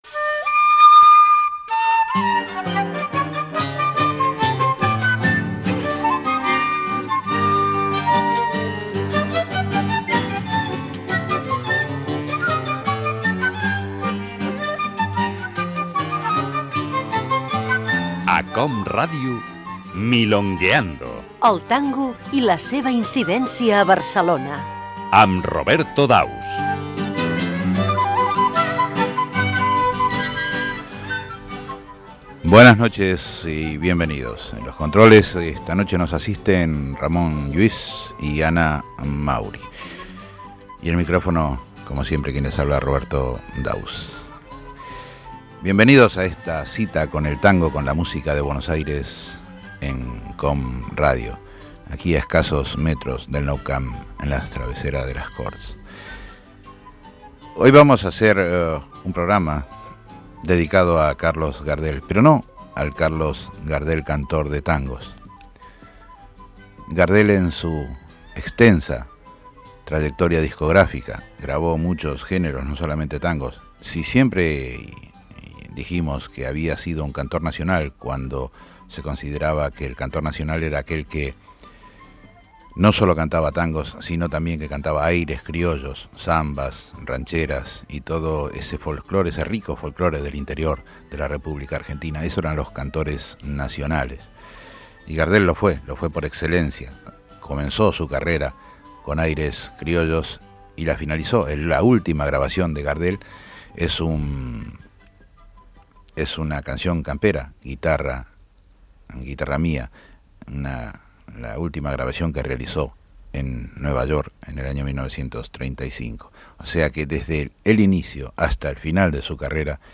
El tango i la seva incidència a Barcelona. Careta del programa, inici d'un programa dedicat a Carlos Gardel.
Musical